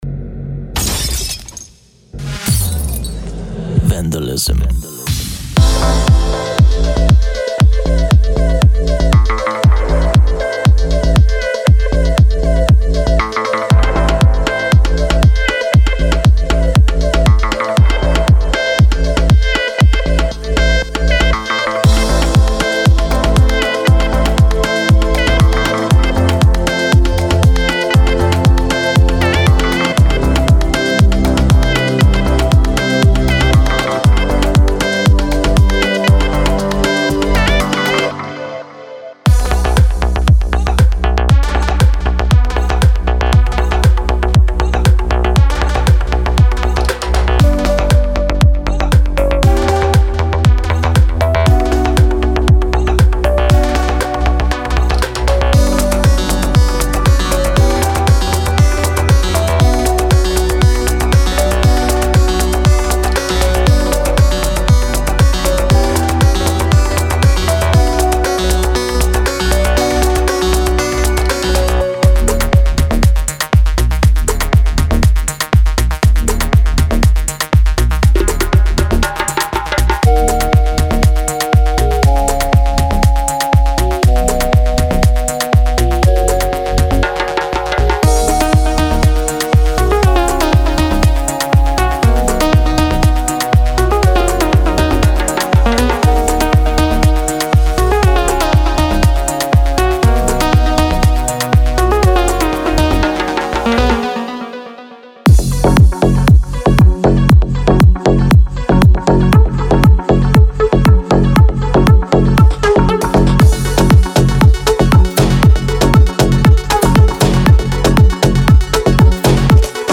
House Melodic Techno Tribal Tropical House
• 7 Bass Sounds
• 32 Lead Sounds
• 5 Pad Sounds
• 9 Pluck Sounds